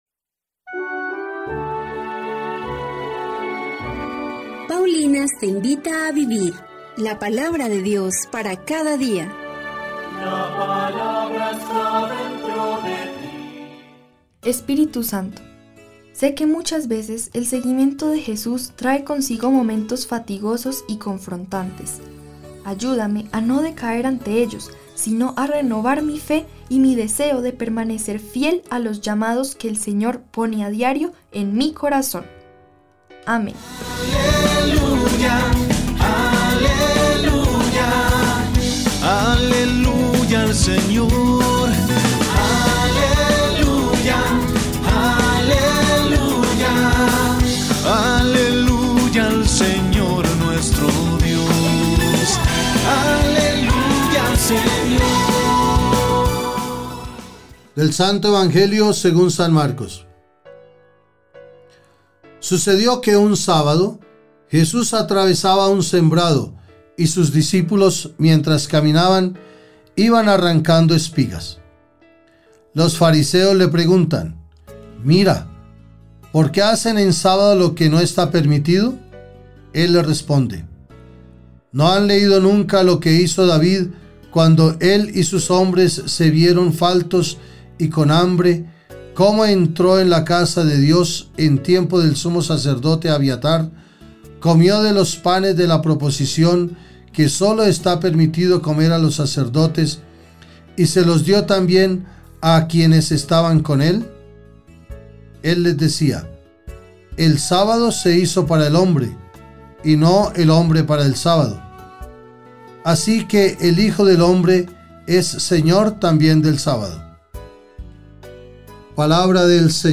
Lectura de la profecía de Jonás 3, 1-5. 10